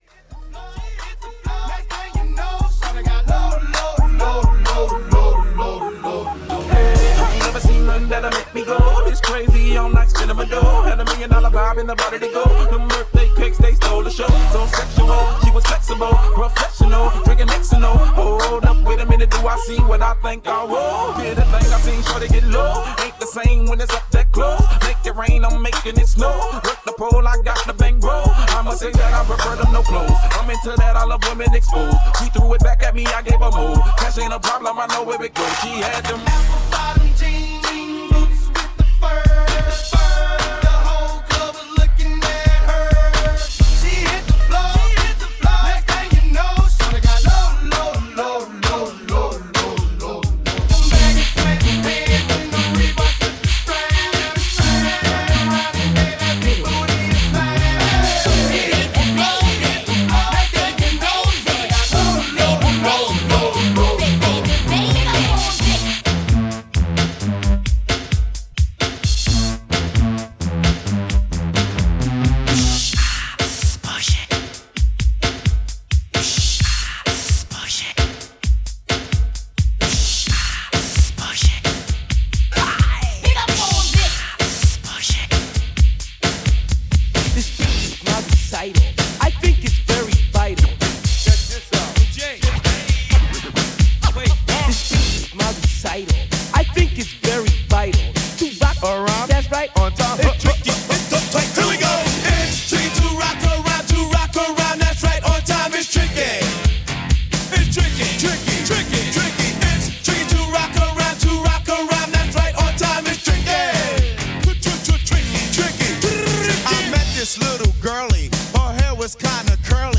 A live party dance mix